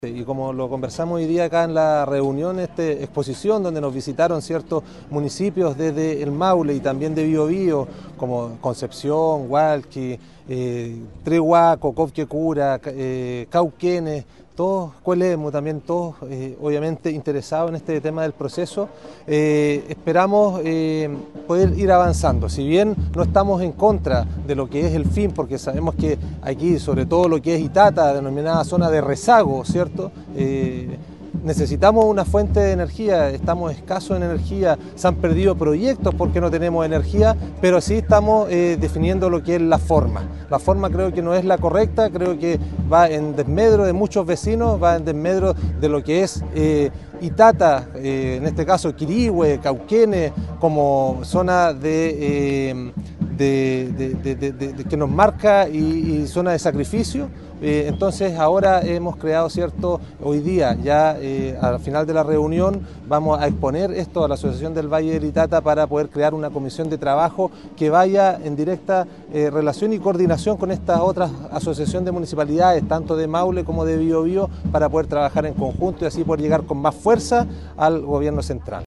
“Con esta unión esperamos poder llegar a la Comisión de Ministros, que nos pueda dar una audiencia, y poder resolver que este criterio que aplicaron ellos en primera instancia, lo notamos irregular en el proceso y, a lo mejor, revertir o mejorar las condiciones que tenemos en este momento”, señaló Eduardo Redlich, alcalde de Quirihue.